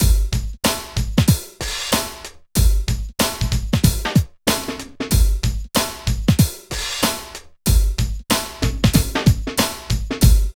101 LOOP  -R.wav